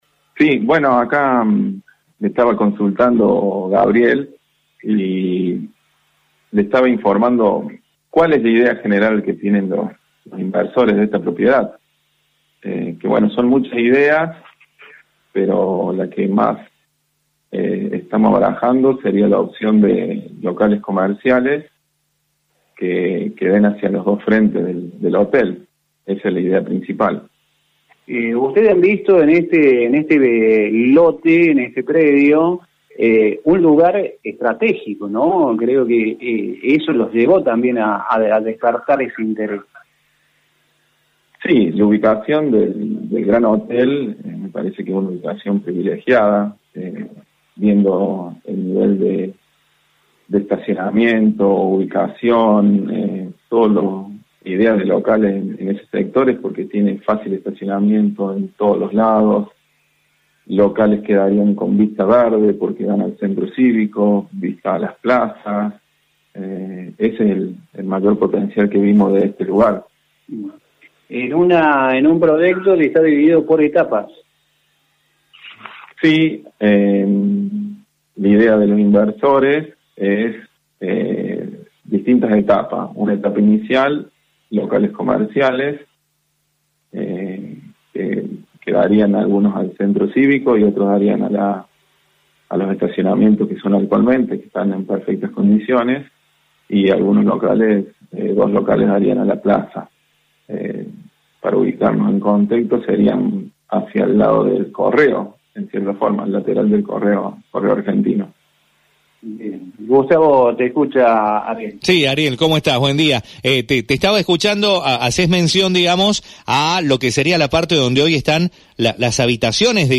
Locales comerciales y ambicioso proyecto para el inmueble del ex Gran Hotel (audio entrevista) – FM Identidad